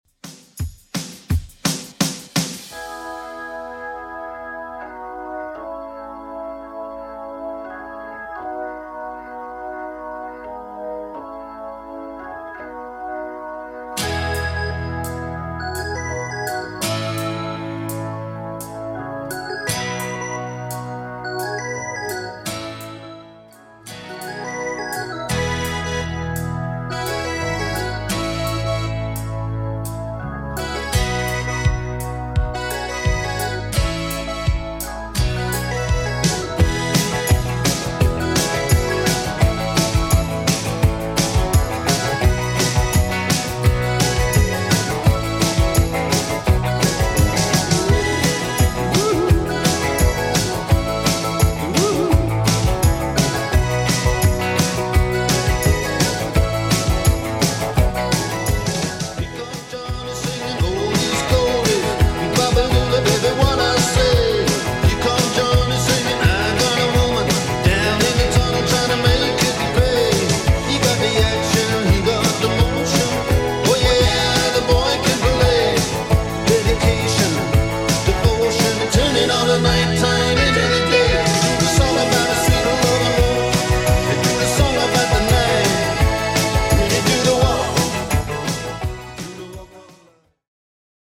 80s Rock Redrum) 170bpm CLEAN